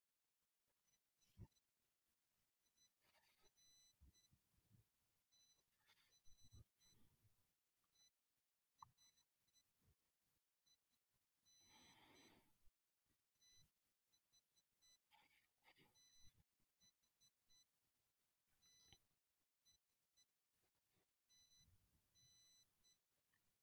High pitch noise: how to fix and how to prevent
Recently, I noticed a strange high pitch that appears mostly when the partner is silent. The attached file is an example of such noise.
It sounds like electrical interference from somewhere.
But 4kHz & 6kHz are particularly strong so require additional treatment …
Notice the two horizontal dashed lines at 4kHz and 6 kHz.